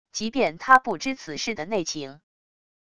即便他不知此事的内情wav音频生成系统WAV Audio Player